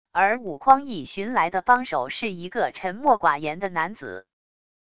而武匡义寻来的帮手是一个沉默寡言的男子wav音频生成系统WAV Audio Player